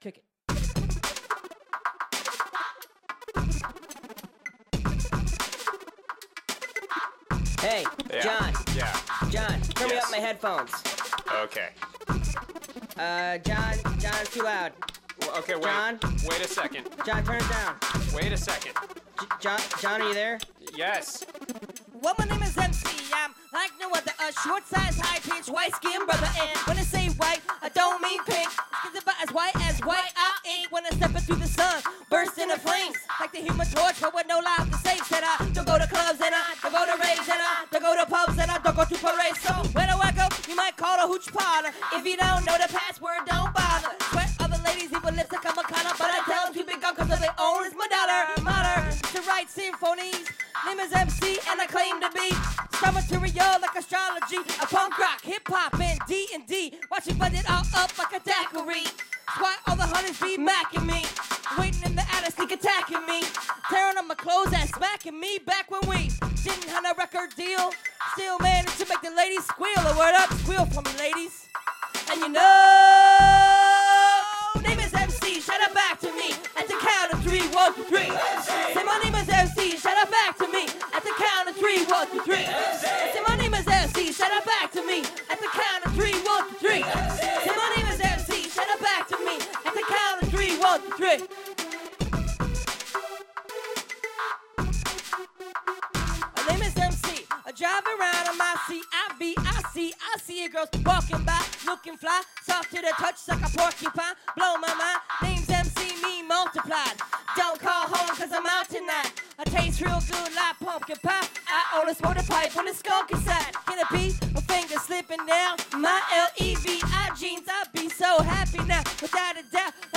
live at bubbas bowling club, soundboard feed